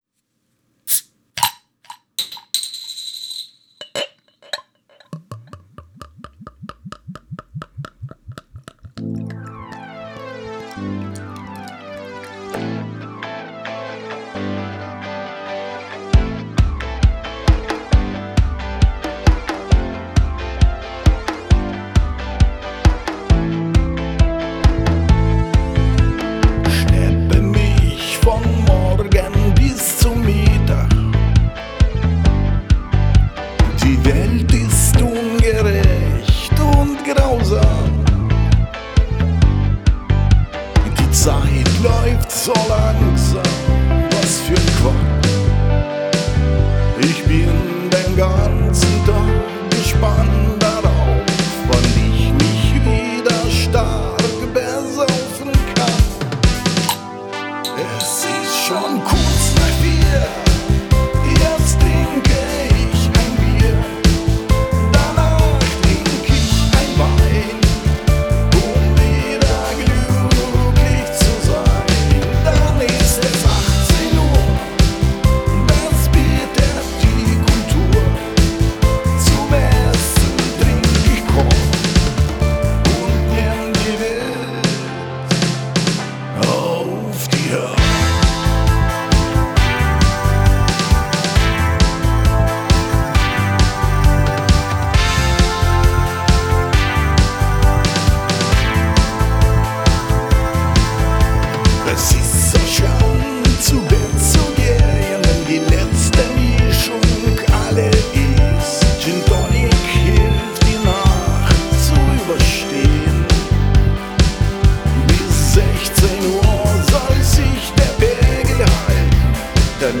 Sie muss nicht unbedingt verwendet werden, aber wer Lust und Zeit hat kann davon gebrauch machen. 24 WAV Audio Spuren, davon 6 sind Vokals. Tempo 134 Vokal: Es sind 6 Spuren insgesamt. 5 Takes im rohen Zustand und eine Orig.